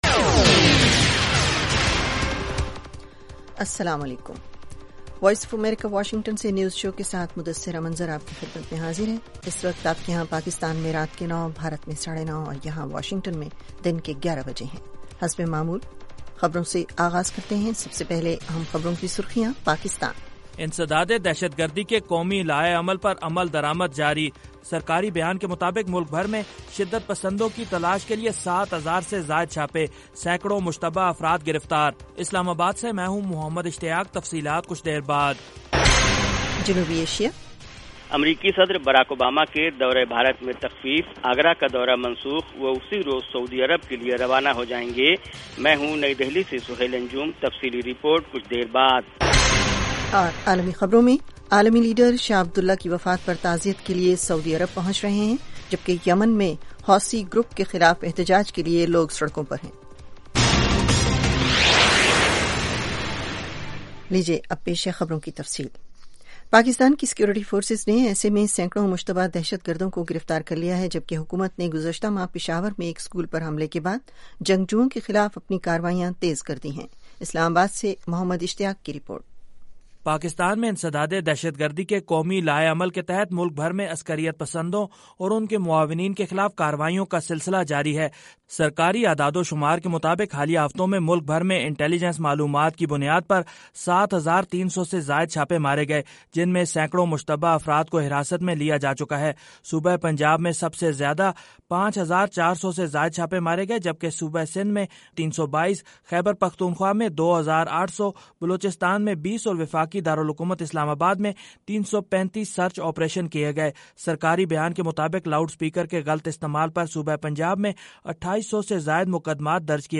9:00PM اردو نیوز شو